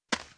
石子落地.wav